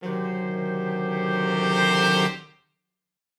Index of /musicradar/gangster-sting-samples/Chord Hits/Horn Swells
GS_HornSwell-D7b2sus4.wav